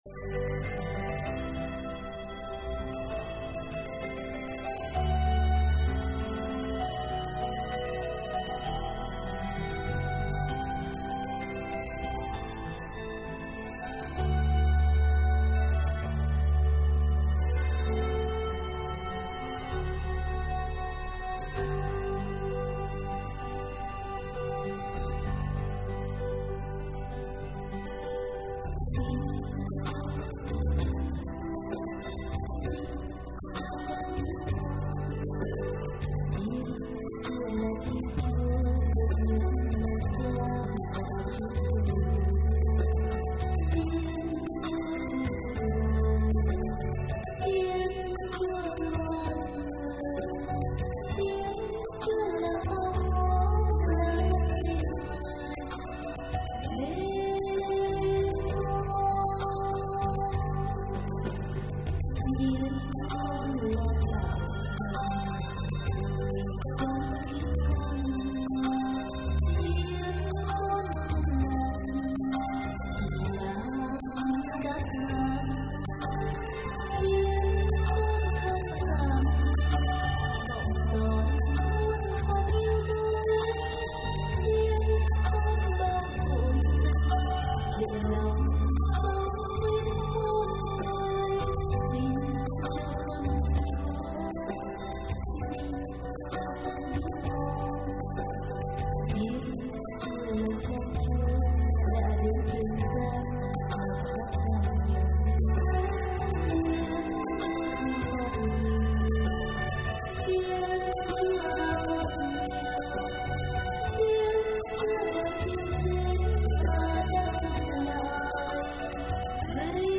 thể hiện hơn 90 bài hát Thánh Ca.